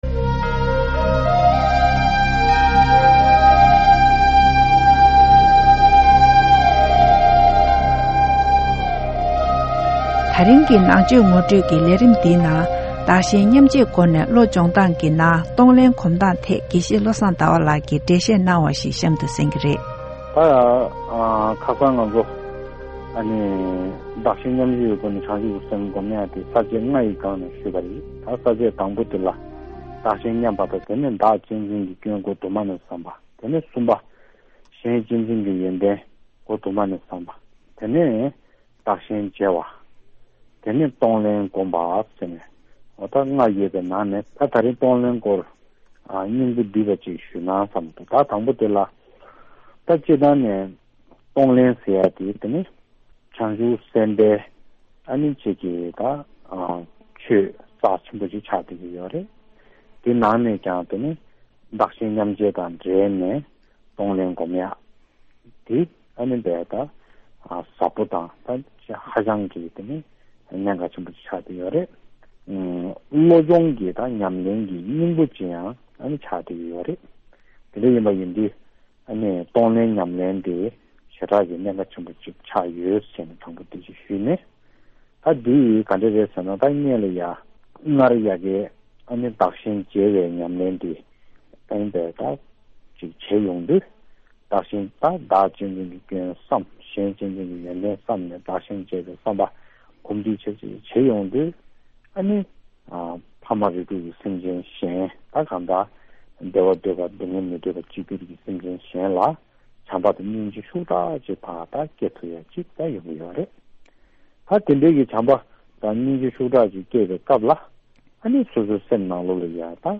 interview series